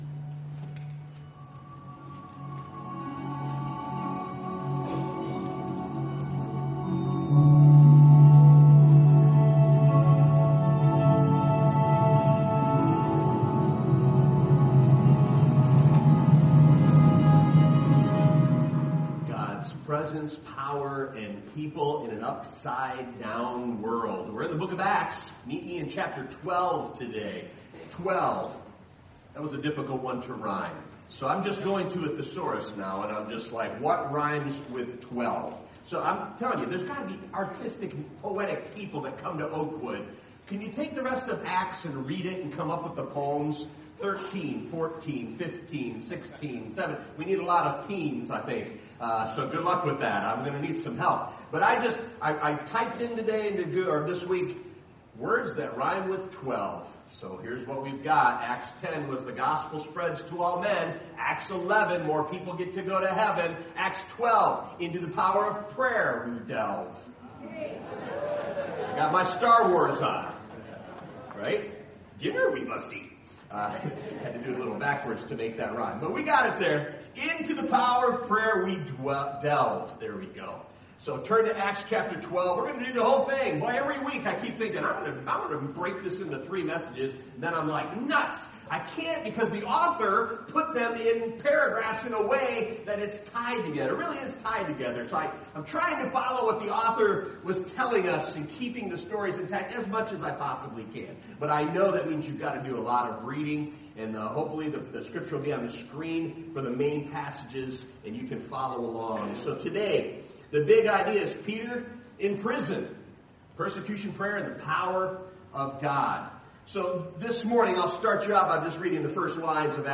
2026 Current Sermon Peter In Prison...